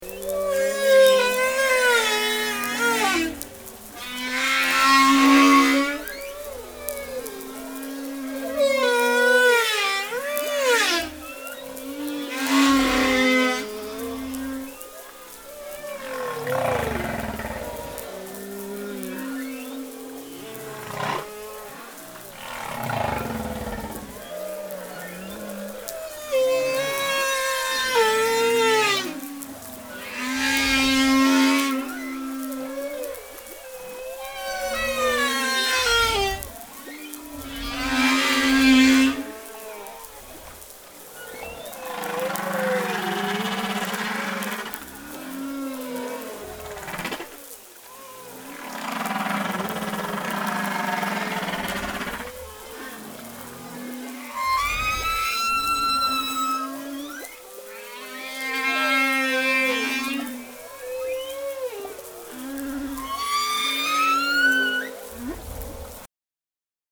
Les vocalisations d’une baleine à bosse.